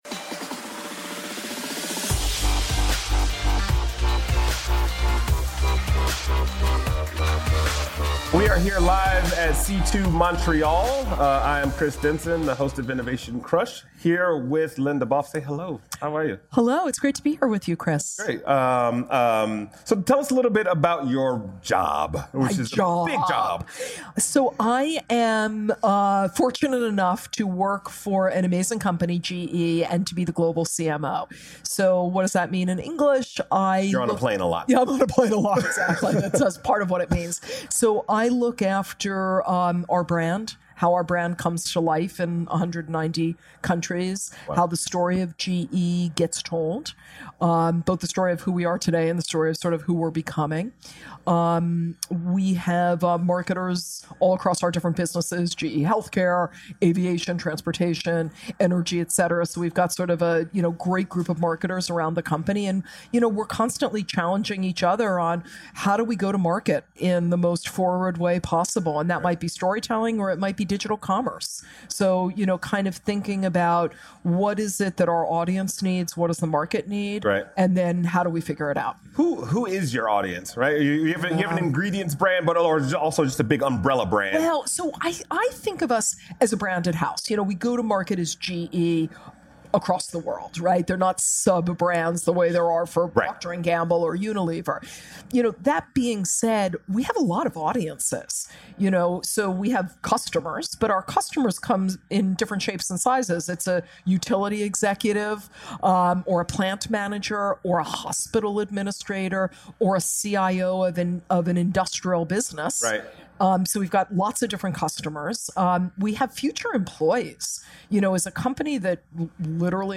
Recorded live at C2 Montreal